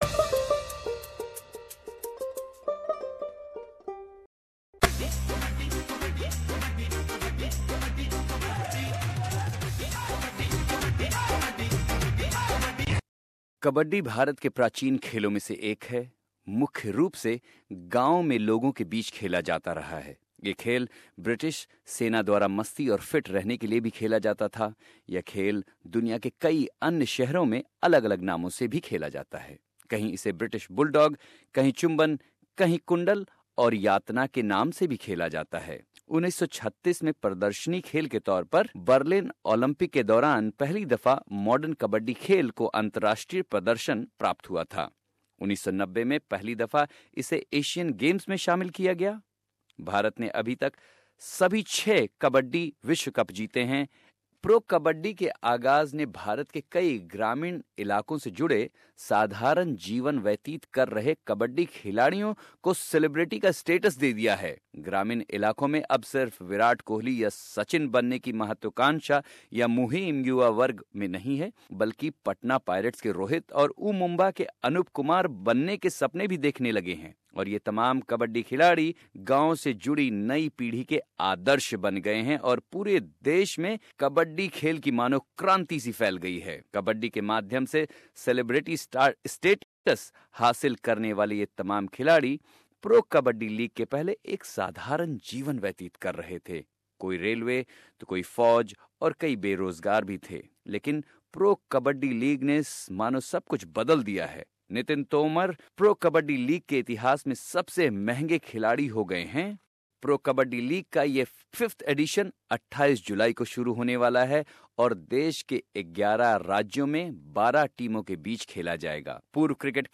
भारतीय कबड्डी के कप्तान और अर्जुन अवॉर्ड एसबीएस हिंदी से बात करते थे और उम्मीद करते हैं कि इस साल प्रो कबड्डी लीग देश के लोगों के बीच अपनी लोकप्रियता को और बढ़ाएगी।